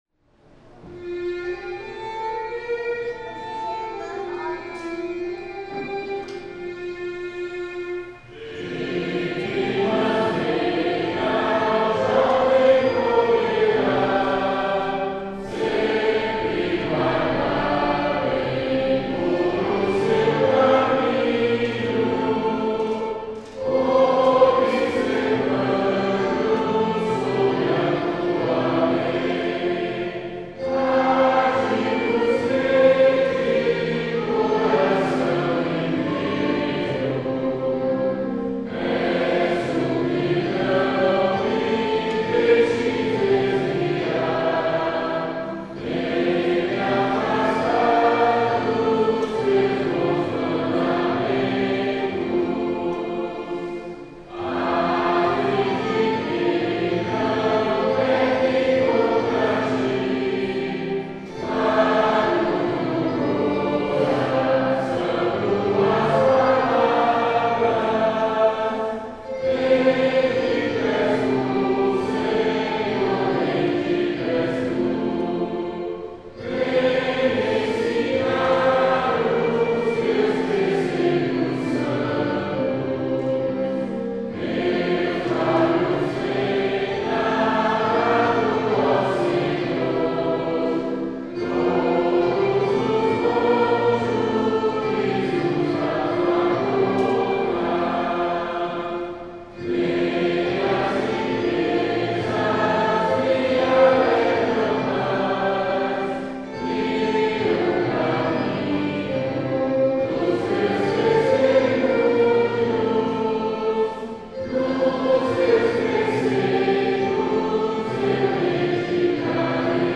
Modo: hipojônio
Harmonização: Claude Goudimel, 1564